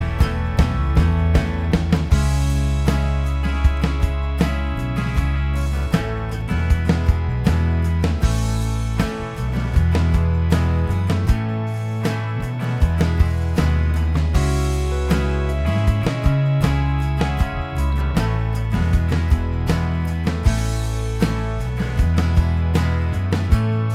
Minus Lead Guitar Pop (2000s) 3:16 Buy £1.50